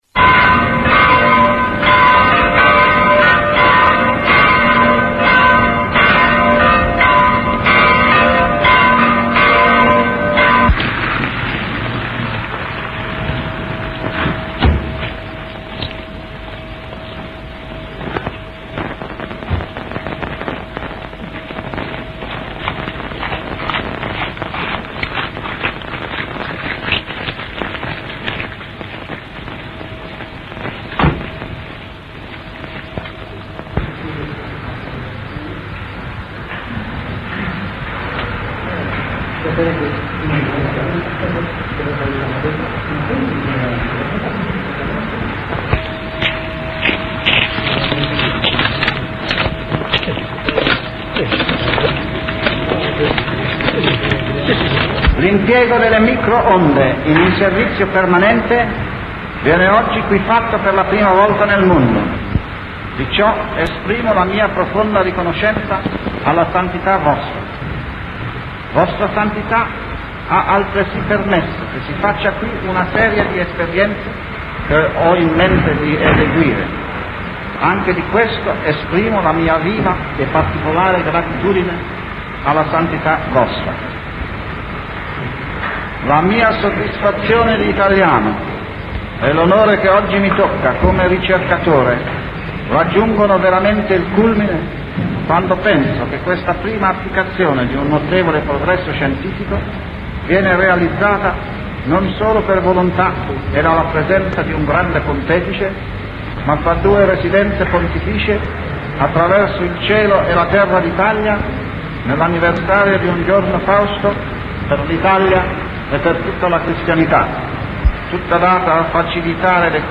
ASCOLTA LA VOCE DI GUGLIELMO MARCONI